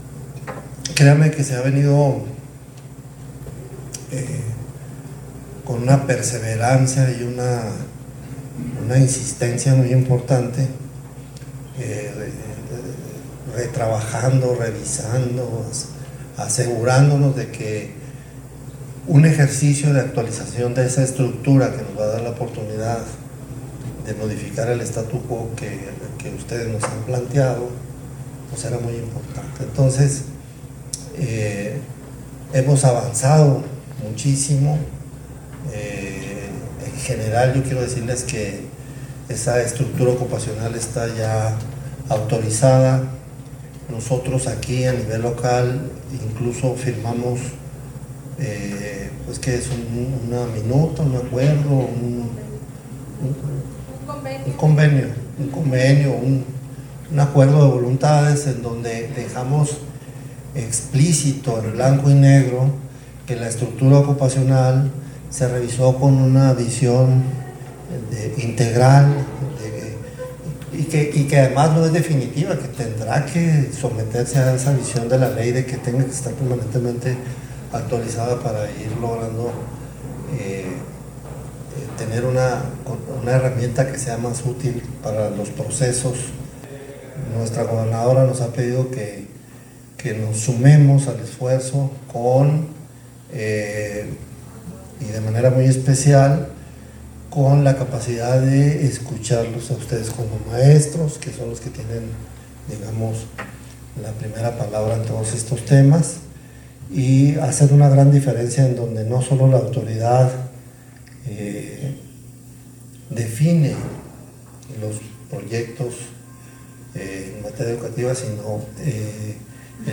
audio-secretario_de_educacion_y_deporte-hugo_gutierrez_davila.mp3